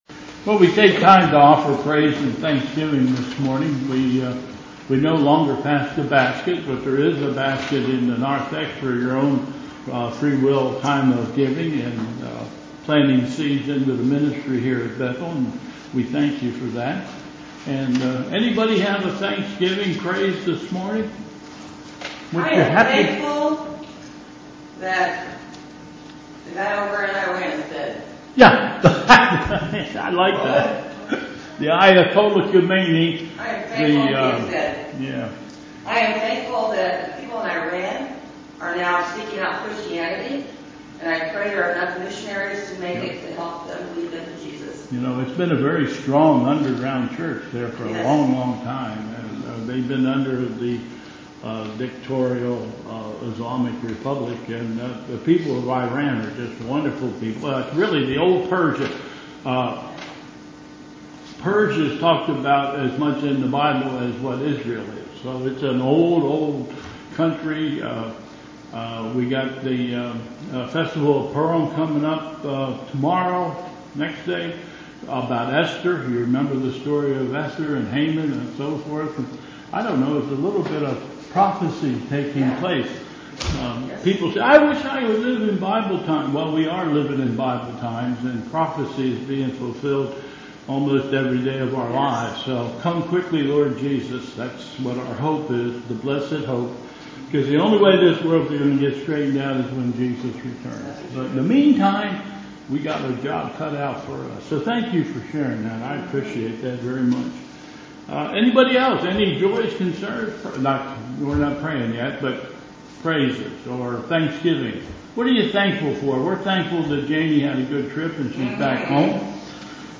Bethel Church Service
Offerings of Praise, Thanks and Doxology
....and singing of the Doxology